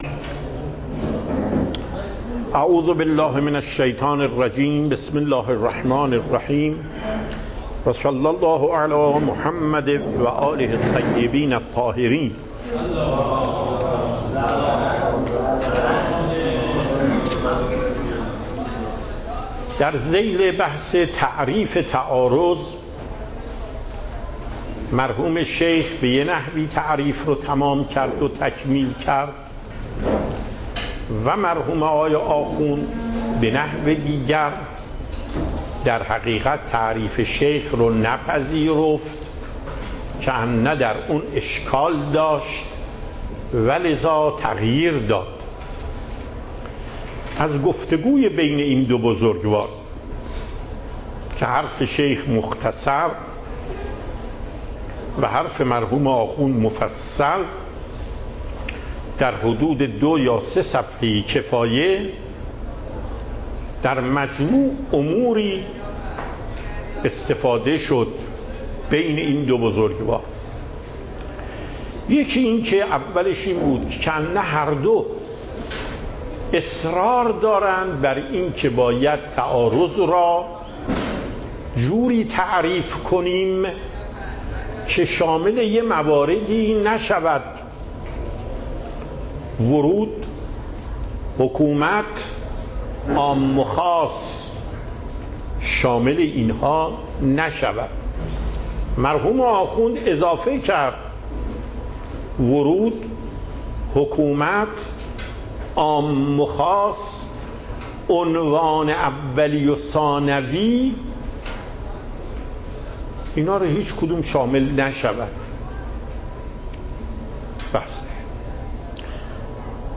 صوت و تقریر درس پخش صوت درس: متن تقریر درس: ↓↓↓ موضوع: التعادل و التراجیح/معنى التعارض /تعریف تعارض خلاصه ای از مباحث گذشته در ذیل بحث تعریف تعارض ،مرحوم شیخ به یک نحوی تعریف را تکمیل کرد و مرحوم آخوند به نحو دیگر.